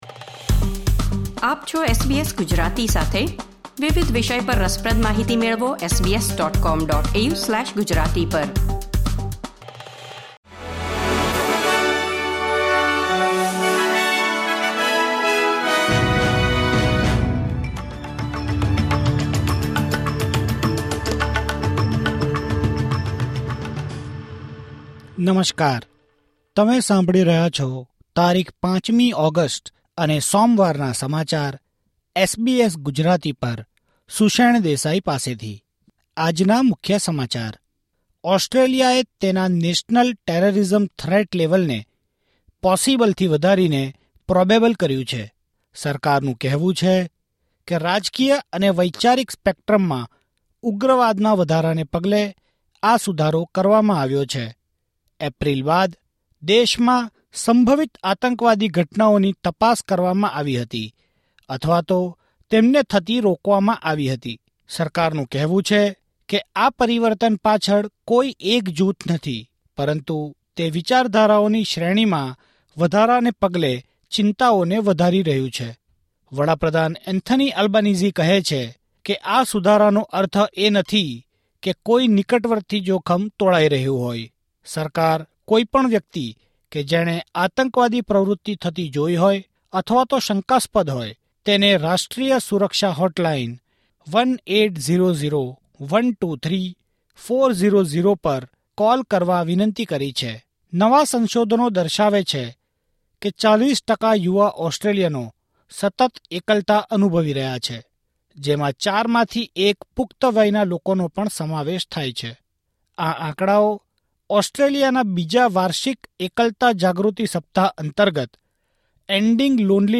SBS Gujarati News Bulletin 5 August 2024